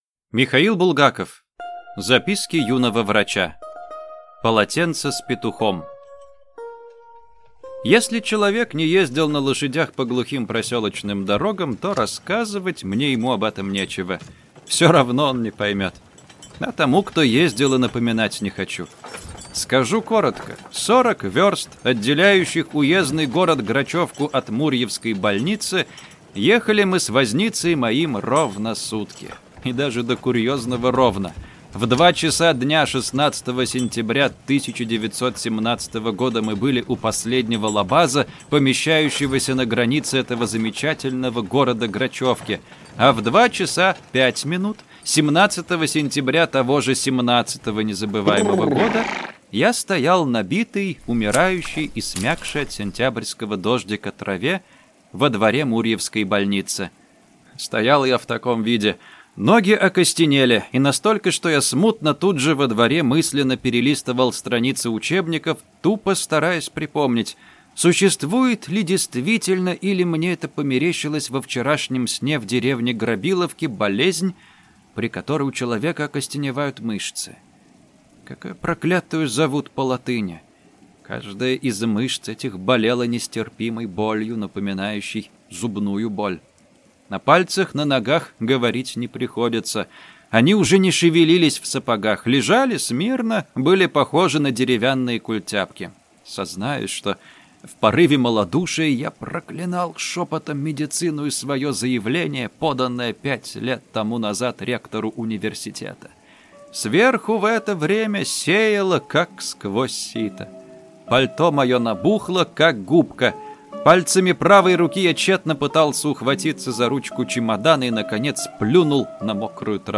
Аудиокнига Морфий (сборник) | Библиотека аудиокниг